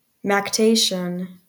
Ääntäminen
Ääntäminen US Haettu sana löytyi näillä lähdekielillä: englanti Käännöksiä ei löytynyt valitulle kohdekielelle. Määritelmät Substantiivi (archaic) The act of killing a victim for sacrifice .